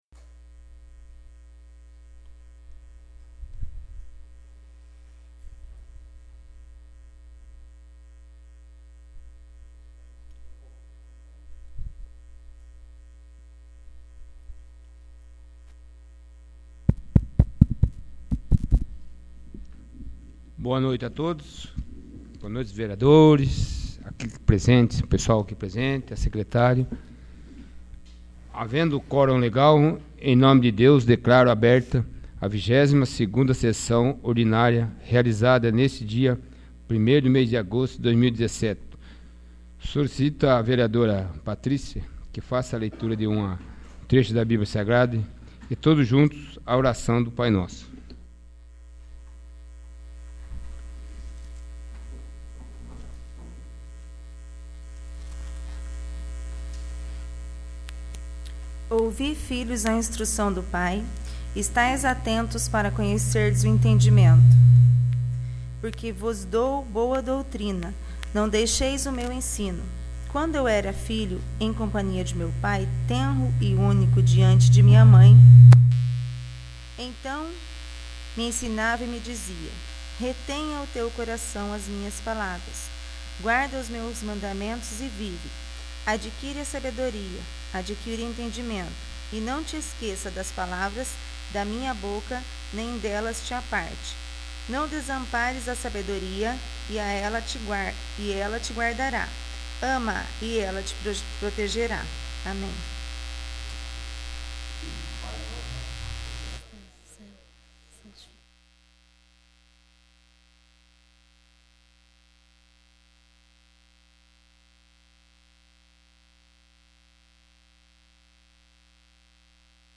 22º. Sessão Ordinária